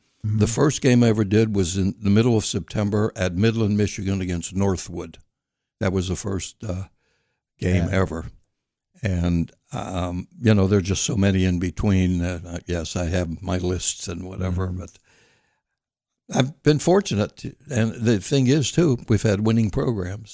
and on Indiana in the Morning on Friday, he said that he has been lucky in the 600 football games to call a lot of winning campaigns.